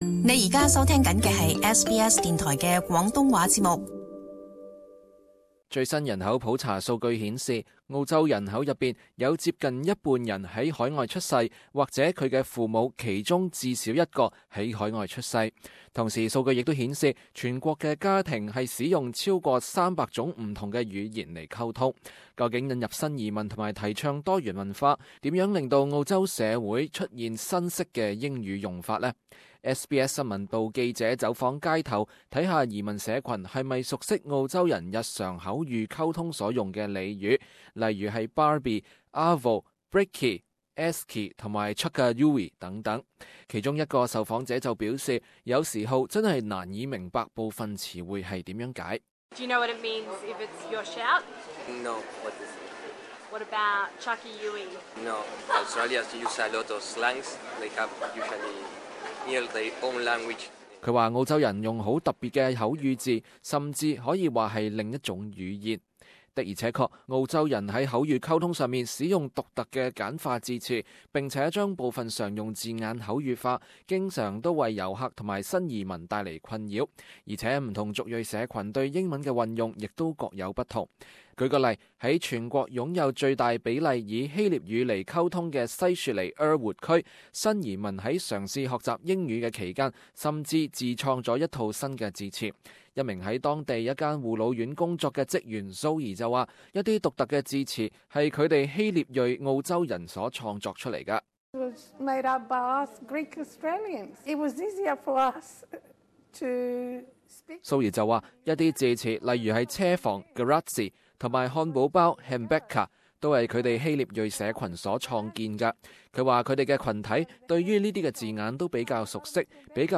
SBS新聞部記者走訪街頭，看看移民社群是否熟悉澳洲人日常口語溝通中所用的俚語，例如「barbie」、「arvo」、「brekkie」、「eskie」及「chuck a uey」等。